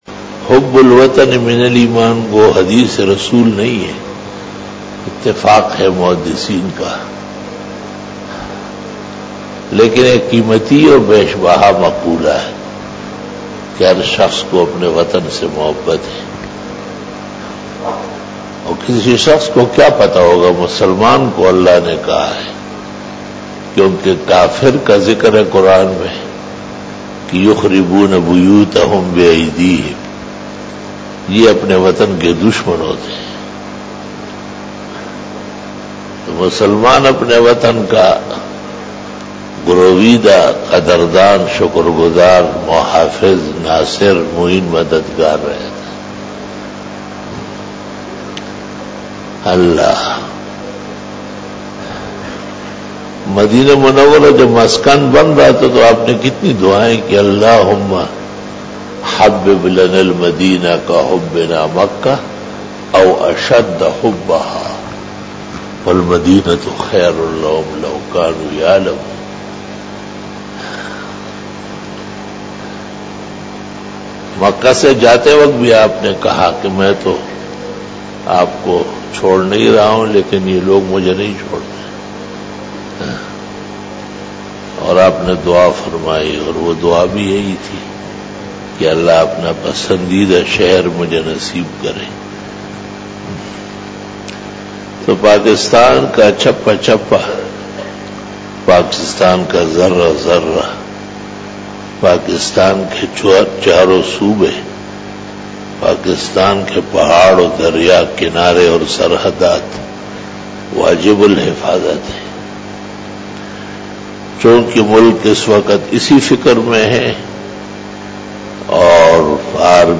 After Asar Byan
بیان بعد نماز عصر بروز بدھ